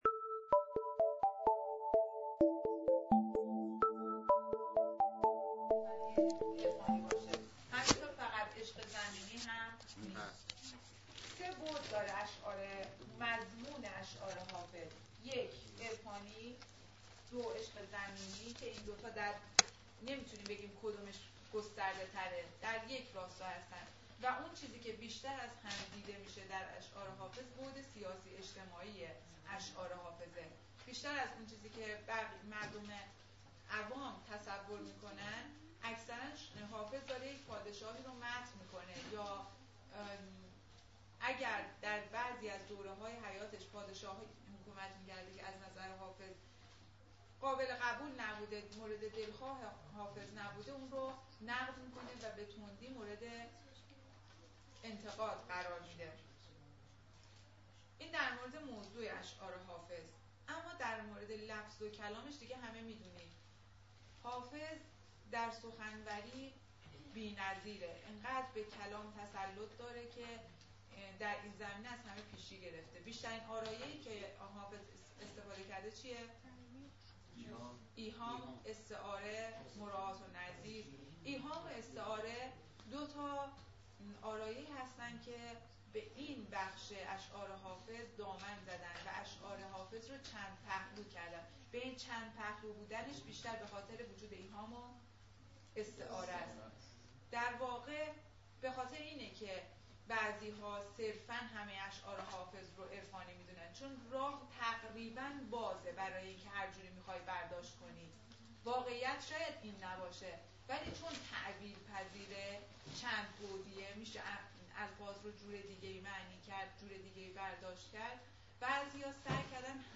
زندگینامه حافظ و پادکست صوتی توضیحات استاد آماده شد.